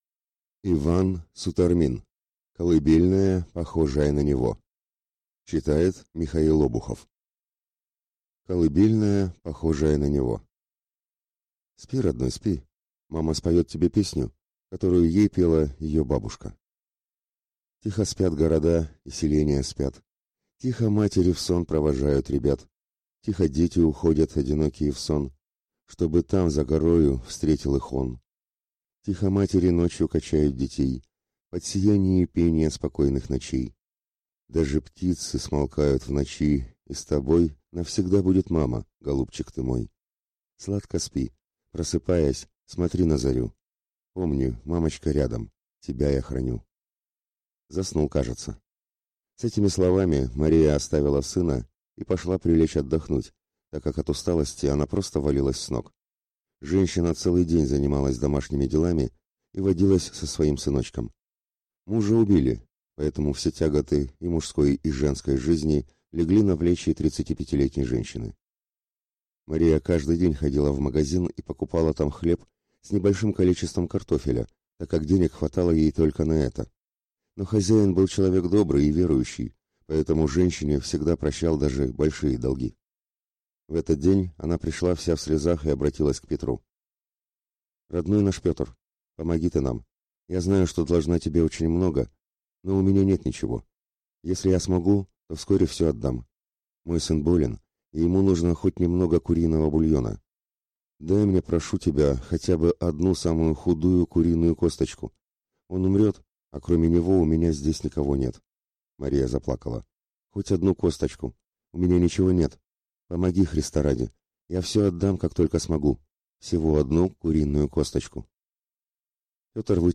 Аудиокнига Колыбельная, похожая на Него | Библиотека аудиокниг